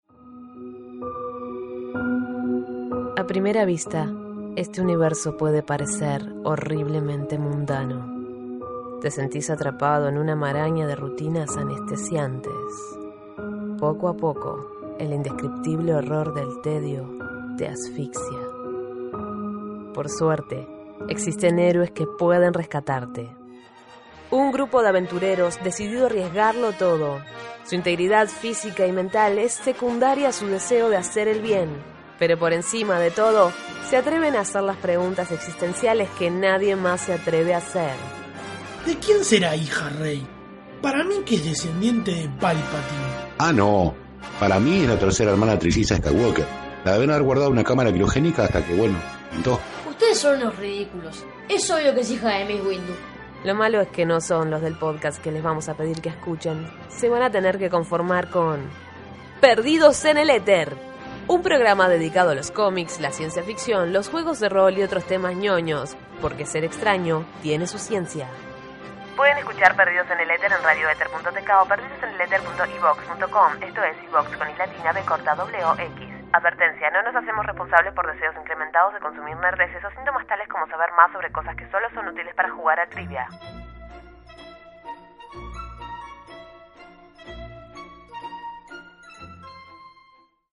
Spot promocional de Perdidos En El Eter, año 2017.